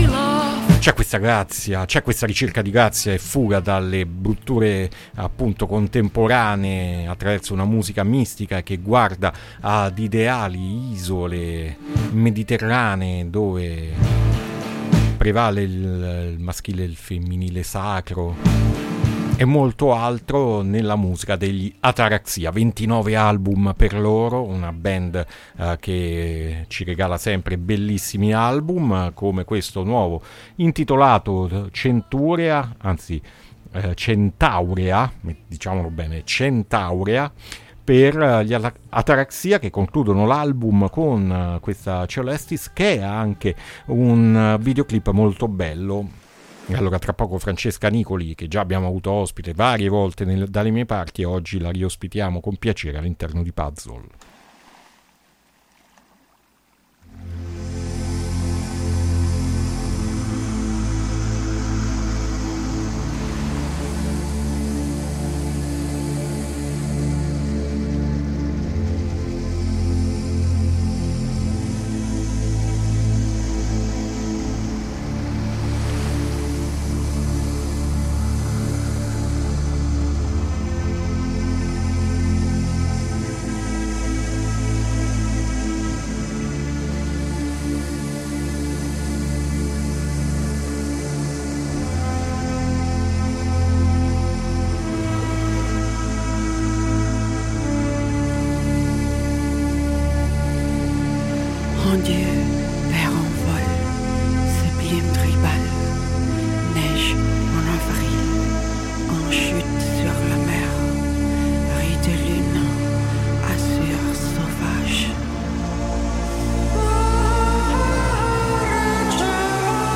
INTERVISTA ATARAXIA A PUZZLE 1-7-2024